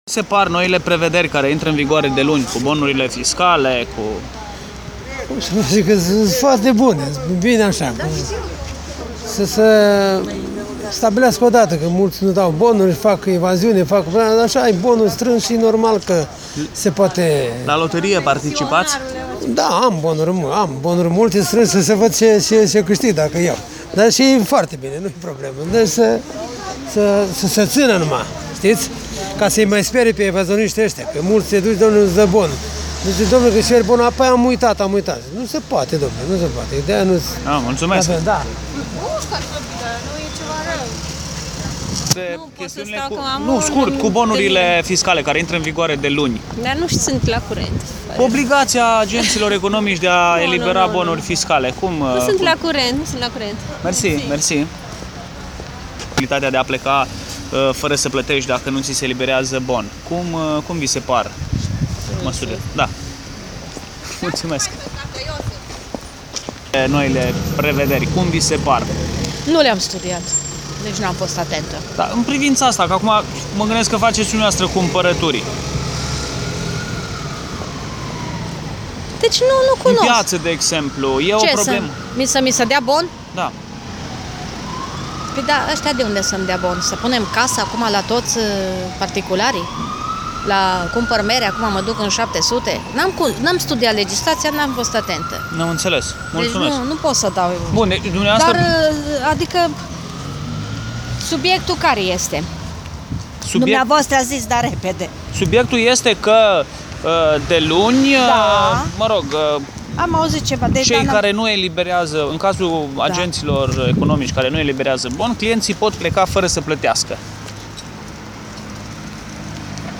Vox-uri-Bonuri-1.mp3